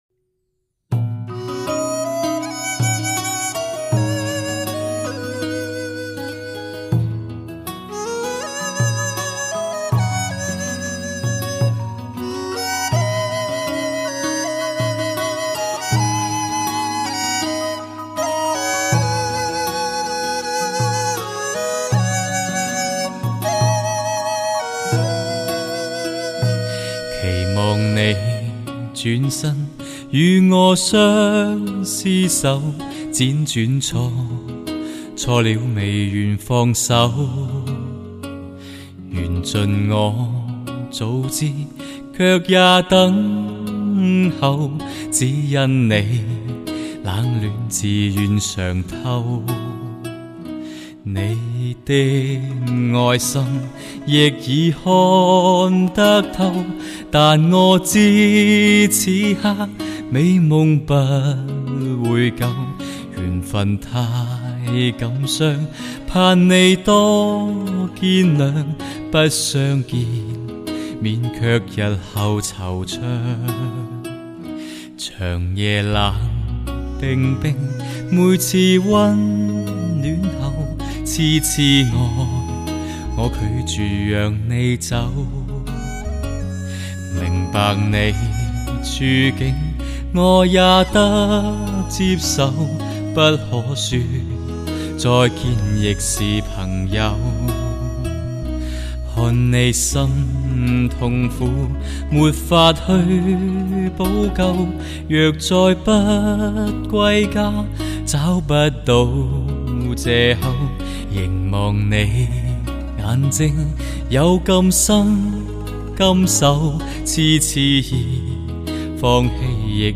音乐风格: 流行
情感动人的歌声演绎 带领你寻回失落了的音符
簡單的配樂
喜欢沉稳心境的演绎，一个成熟男人的内心表白。